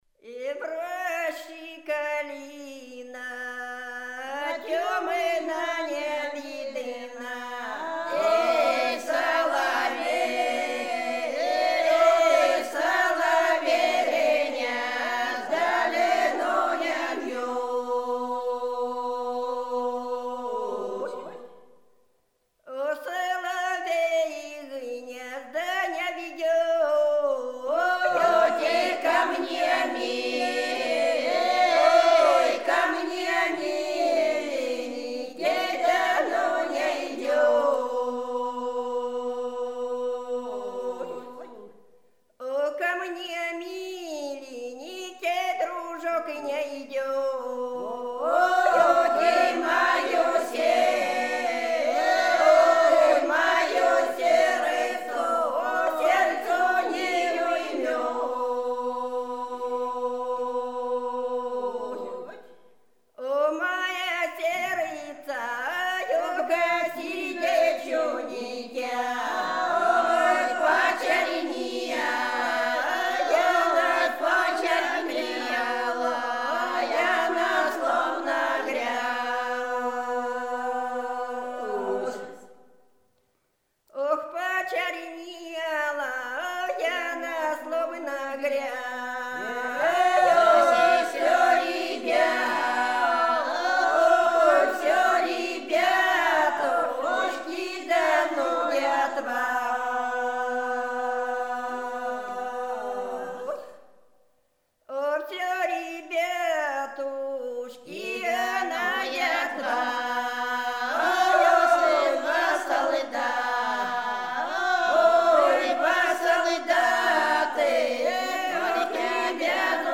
Рязань Секирино «Э, в роще калина», рекрутская.